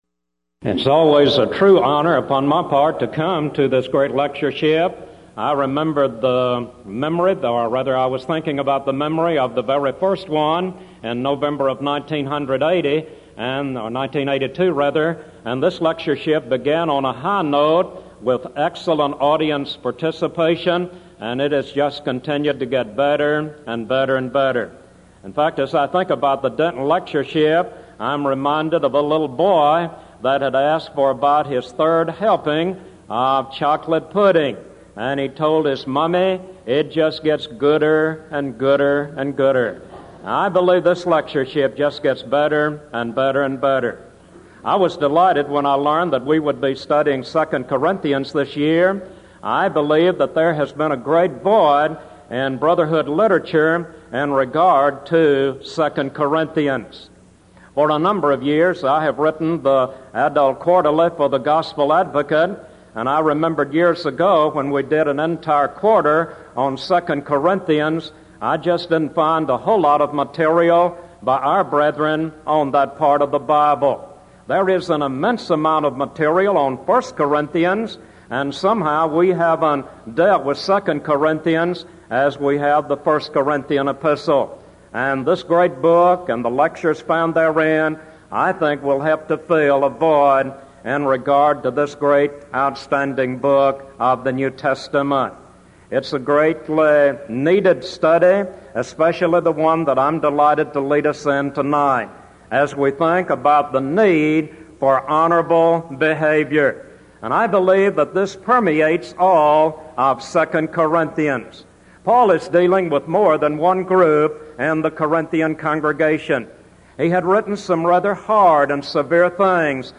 Event: 1989 Denton Lectures
lecture